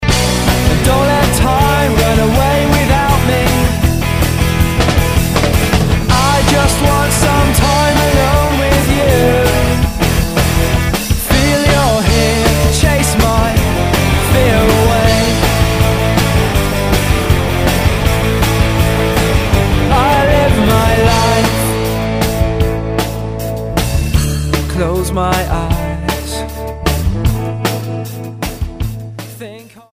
STYLE: Rock
big shameless guitar riffs
pleasingly exuberant guitar solo